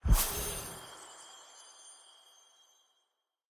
spawn_item_begin2.ogg